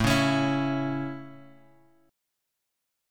A chord {5 4 x 6 5 x} chord
A-Major-A-5,4,x,6,5,x.m4a